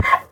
donkey
hit2.ogg